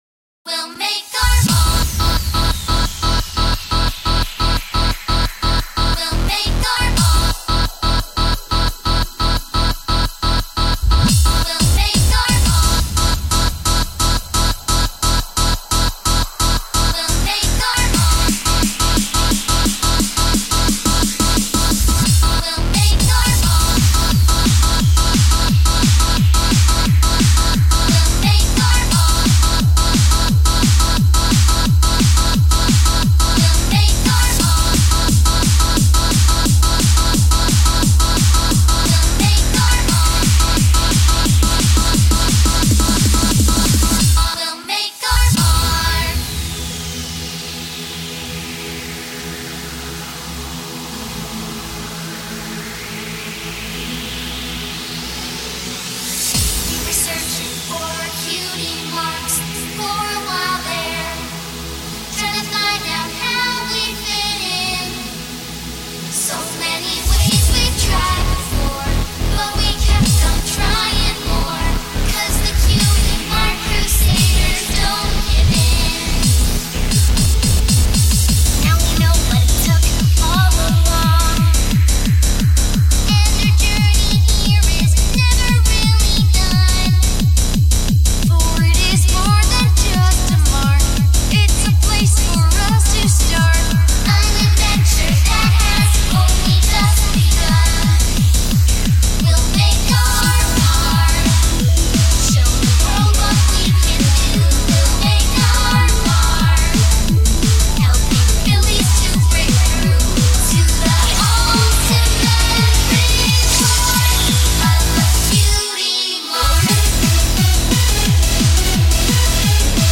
This is like my best shot at doing ukhc.